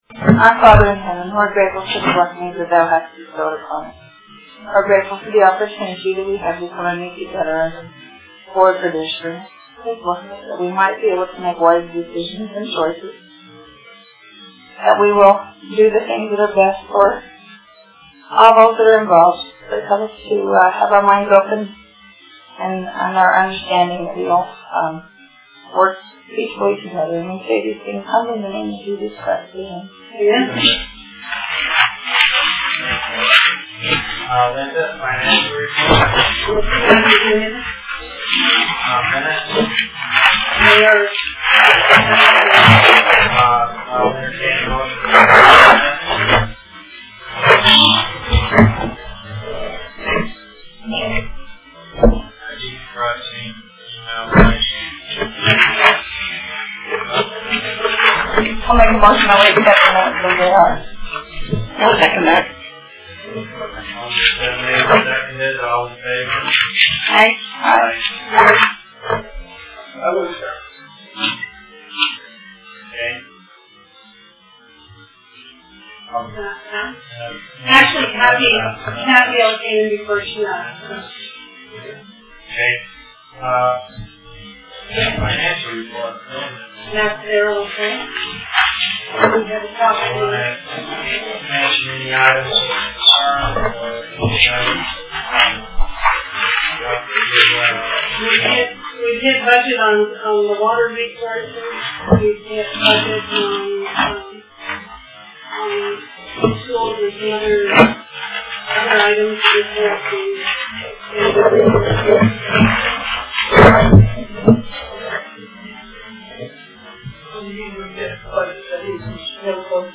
Deer Springs Ranch Board Meeting Friday, June 10, 4 PM UT Kane County Search & Rescue Bldg.